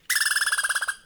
squirrel.ogg